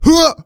XS发力04.wav
XS发力04.wav 0:00.00 0:00.47 XS发力04.wav WAV · 40 KB · 單聲道 (1ch) 下载文件 本站所有音效均采用 CC0 授权 ，可免费用于商业与个人项目，无需署名。
人声采集素材